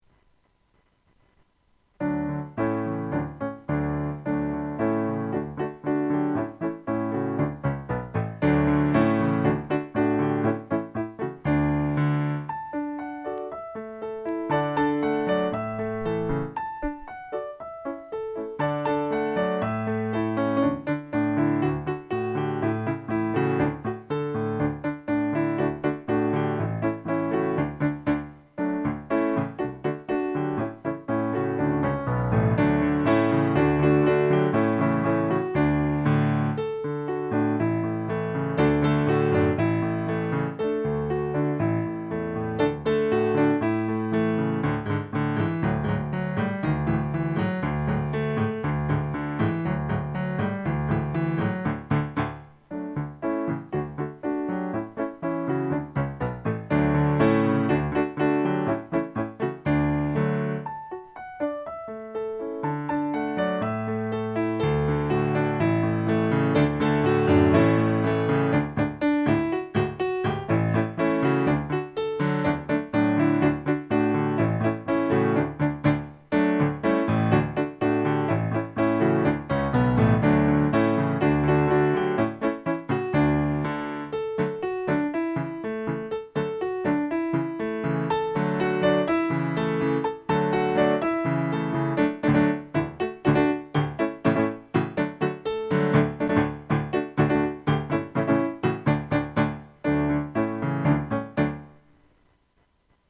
Když jsem přemýšlel na tím, jaké zpívání si pro vás pro tento týden připravím a myslel jsem na to, že jste mi psali, že písničky, které jsem vám nahrál 6.4., jste si zpívali s chutí a dokonce je zpívali i někteří vaši sourozenci a rodiče, rozhodl jsem se, že pro vás nahraji doprovody několika dalších písní.
Přeji vám a případně i vaši sourozencům a rodičům pěkné zpívání a nezapomeňte vždy na vnímání předehry.